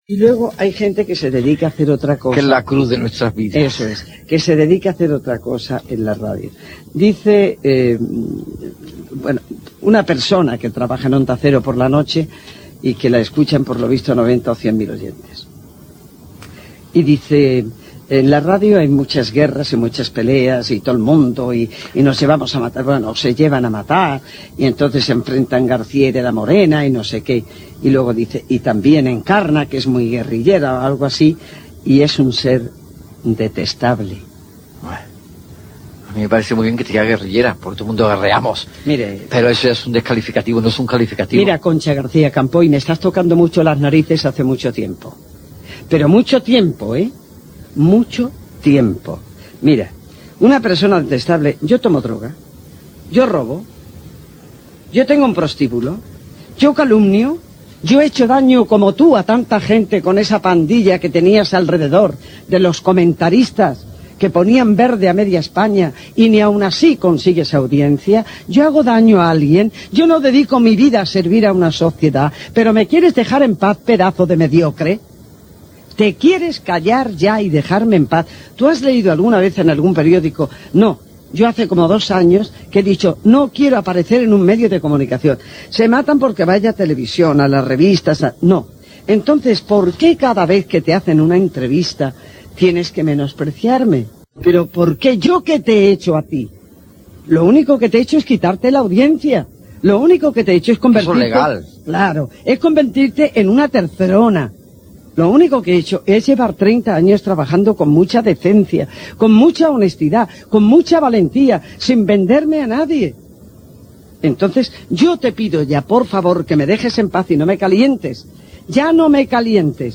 Encarna Sánchez, molt molesta, comenta amb Jaime Peñafiel el fet que la periodista d'Onda Cero Concha García Campoy l'ha qualificat de "detestable" en una entrevista de premsa publicada aquell dia en un diari
Entreteniment